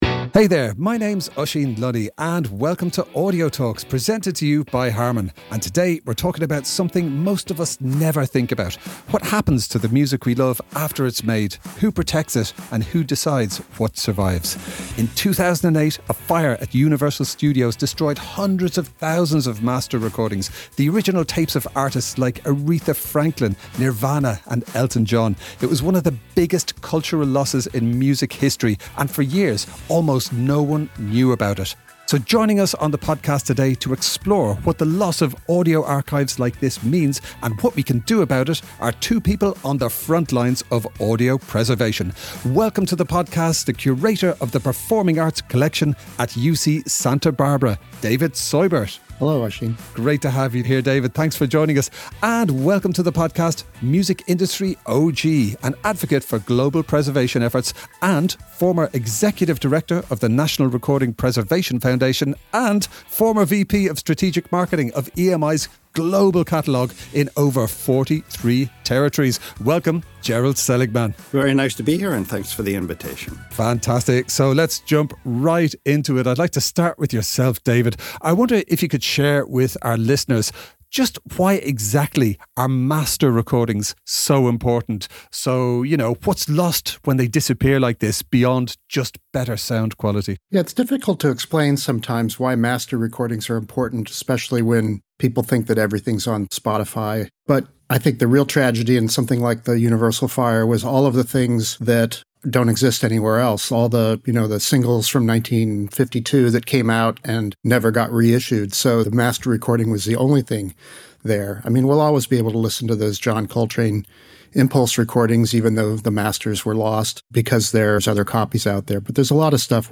Together, they dive into deep-rooted gender inequalities in the industry, discuss latest data and share their personal journeys as activists, researchers, and change-makers. Tune in for a vivid conversation about resilience, representation, and rewriting the soundtrack of the future.